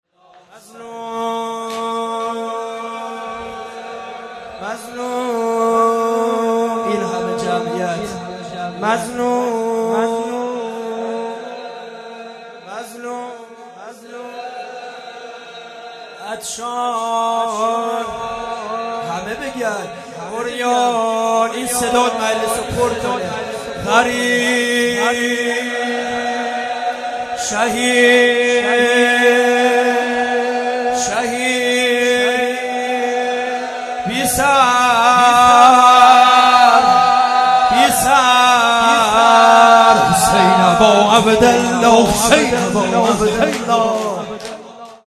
جلسۀ هفتگی